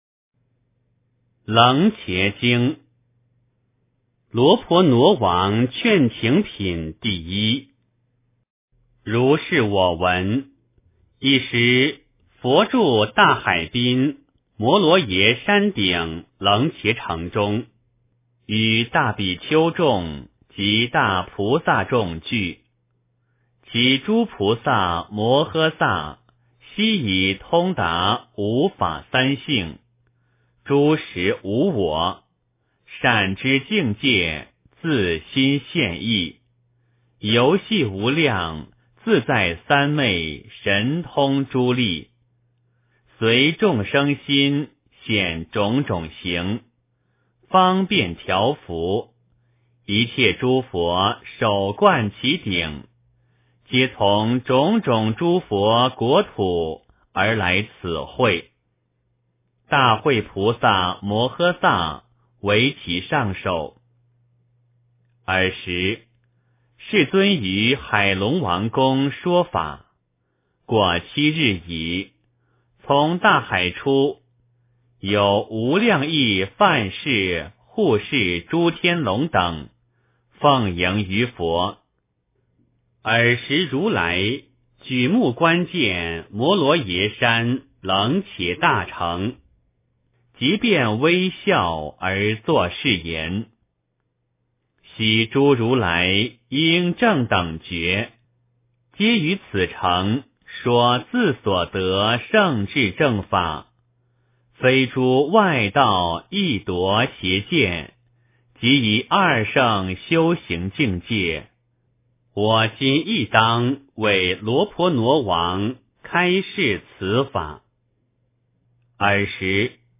楞伽经（罗婆那王劝请品第一） - 诵经 - 云佛论坛